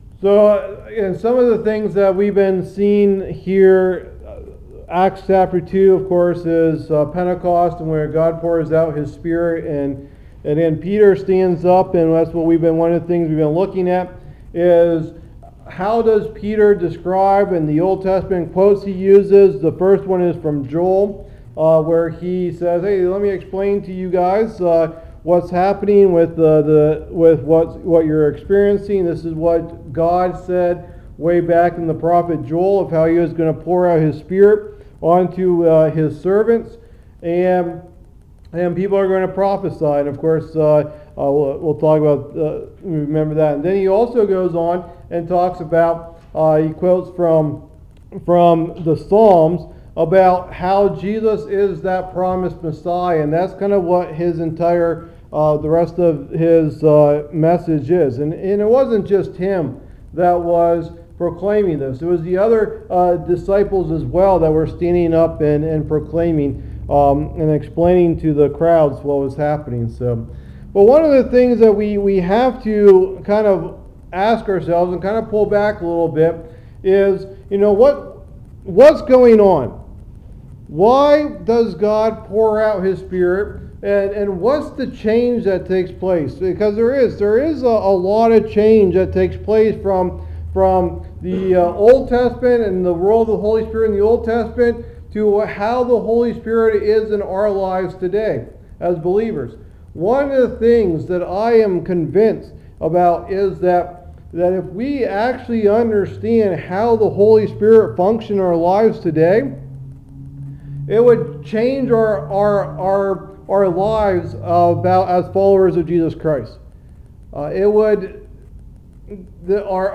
Study #7 of the Book of Acts Bible Study